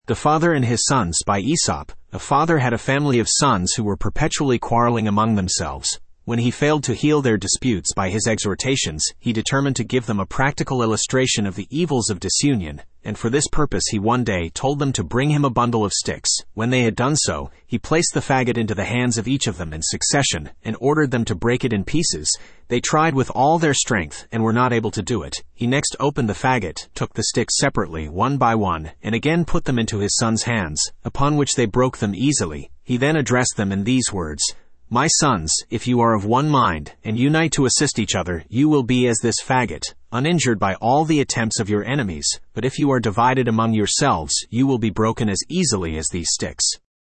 Studio (Male)
the-father-and-his-sons-en-US-Studio-M-367da68b.mp3